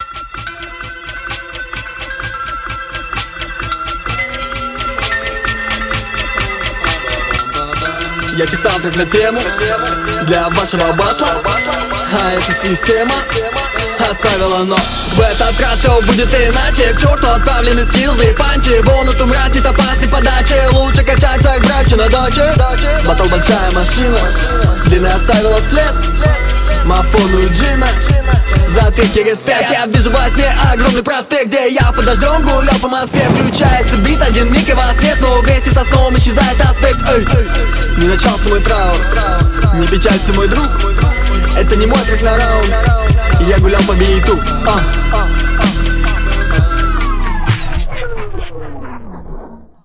Флоу неплохой, но сочетаемость с интересным битом отсутствует.